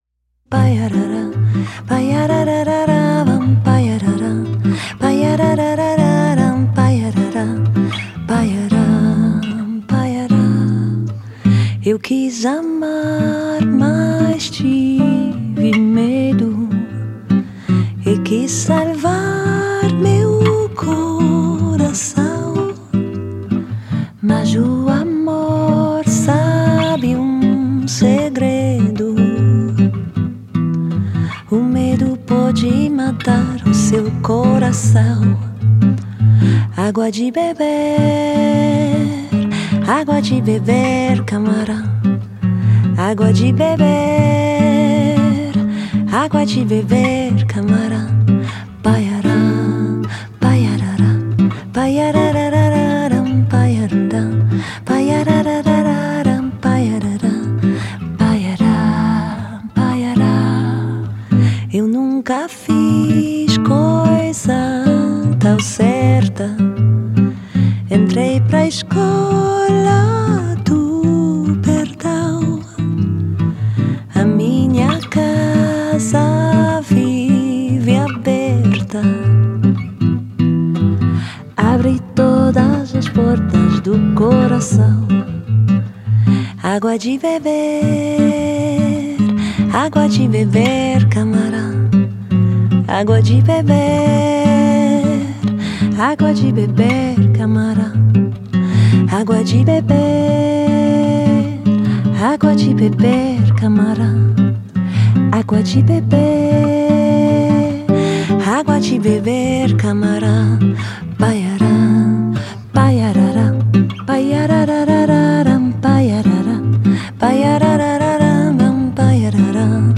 吉他
主唱，钢琴